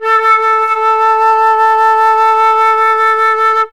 51c-flt17-A3.wav